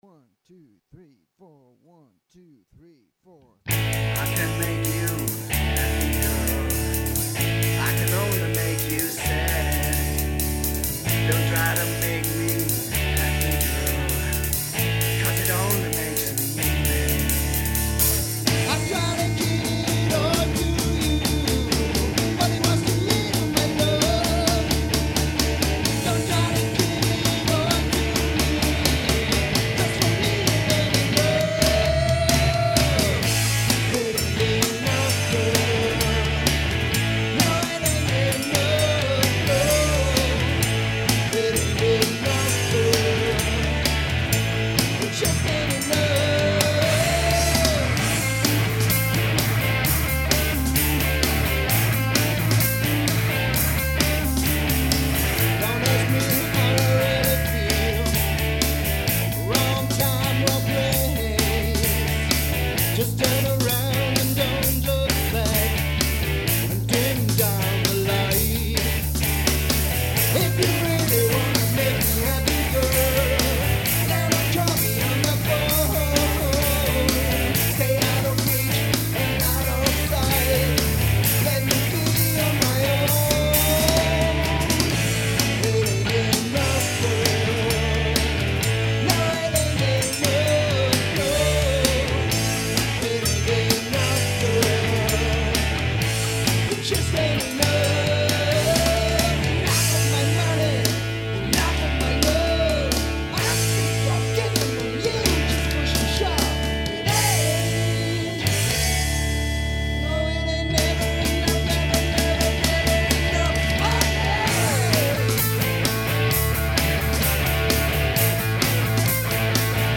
Recorded at MotherMoon Schopfheim
Guitars, Vocals